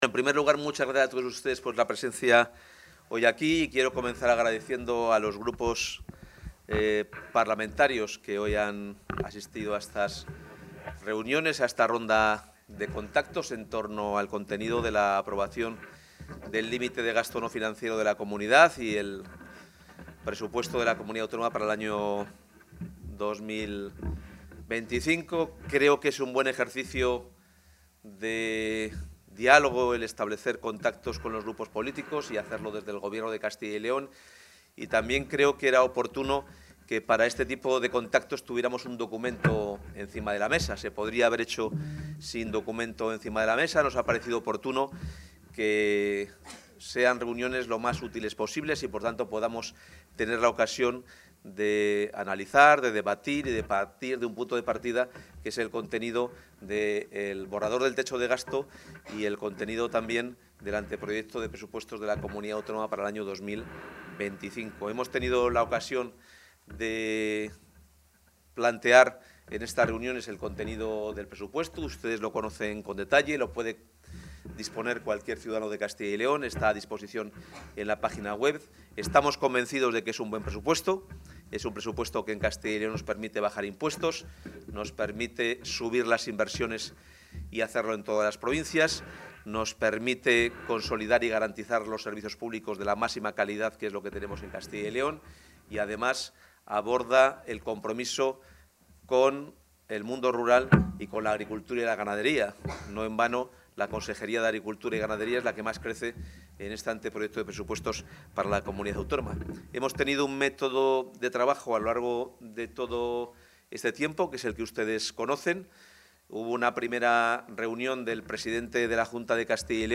El consejero de Economía y Hacienda, Carlos Fernández Carriedo, comparece tras las reuniones con los grupos políticos con representacion parlamentaria en las Cortes de Castilla y León para presentarles el límite de gasto no financiero para los Presupuestos del año 2025.